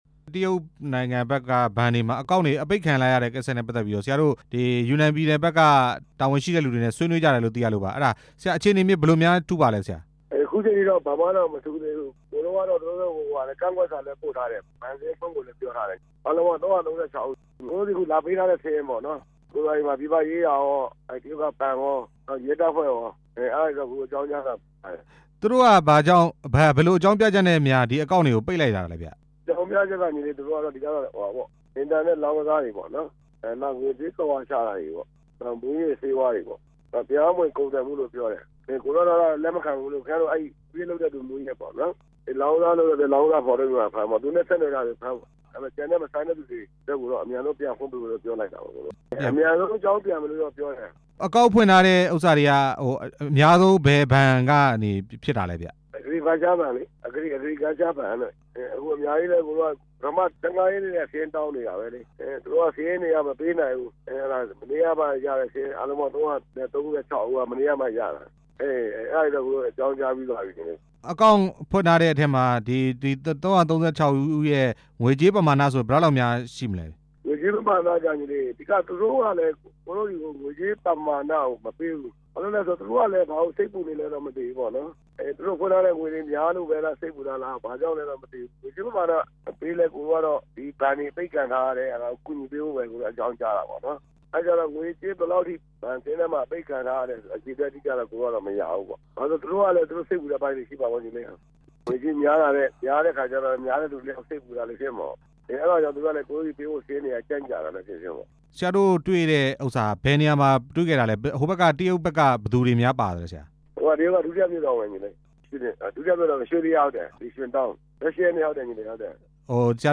ပိတ်ထားတဲ့ တရုတ်ဘဏ်ကိစ္စ မူဆယ်တာဝန်ရှိသူနဲ့ မေးမြန်း ချက်